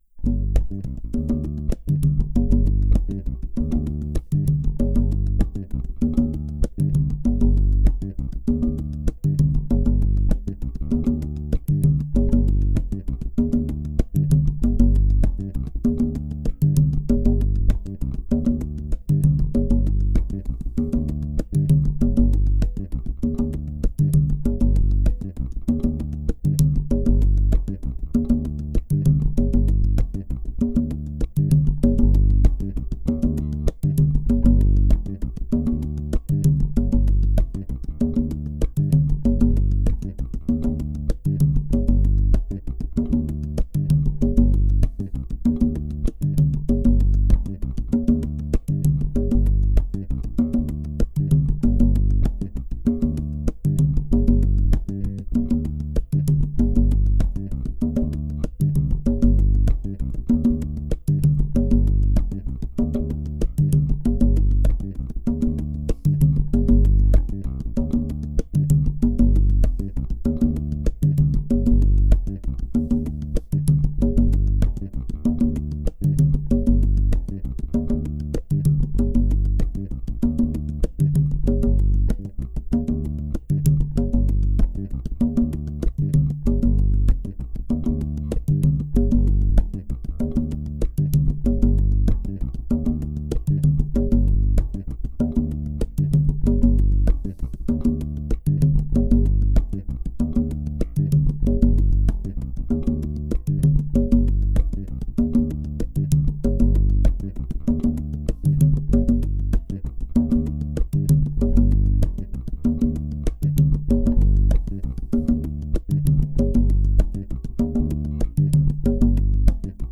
Performance 1, Bass with Congas – Audio Example 1
[35] The tempo is consistent with some fluctuation.
The tempo sounds consistent with a slight dip at around 77 seconds.
Good solid, steady feel throughout.